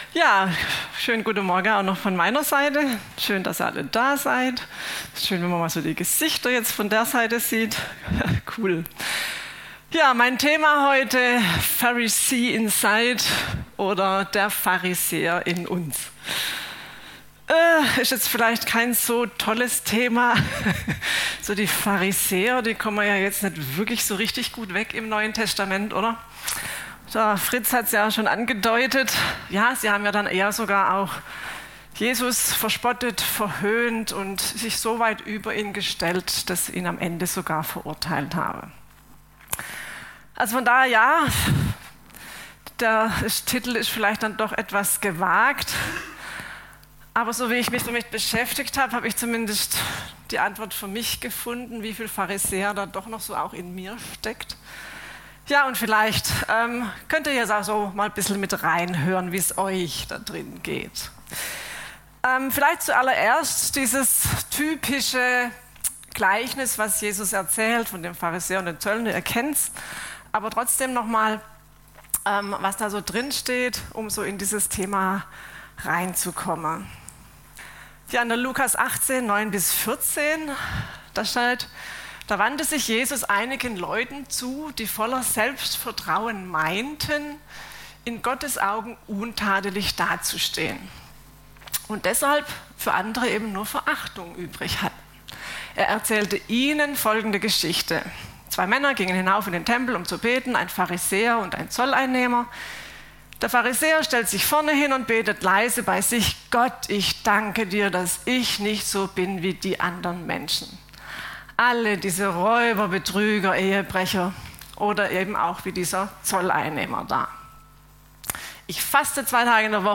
Predigt vom 18. Juni 2023 – Süddeutsche Gemeinschaft Künzelsau